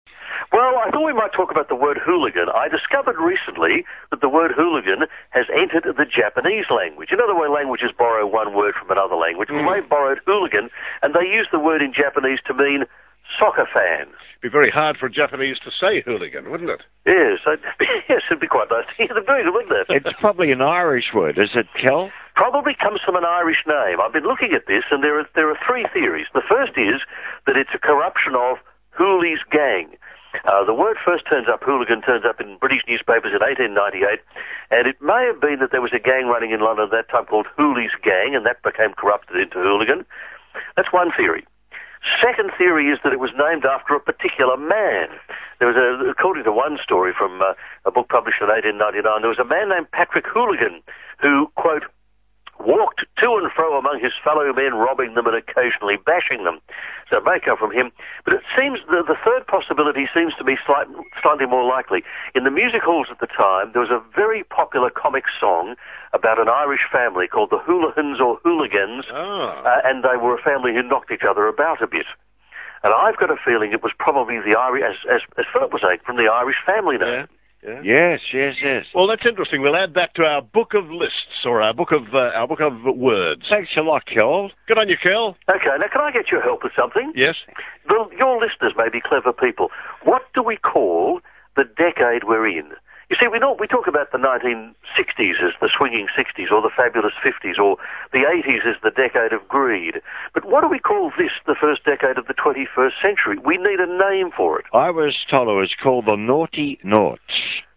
Australian English, general
Australian English shows a shift of diphthongs down and back in phonological space when compared with RP. In this respect is it similar to Cockney English, e.g. tray would be [trɛi] rather than [trei]. What is also typical is a raising of short front vowels so that bad sounds like bed and bed like bid.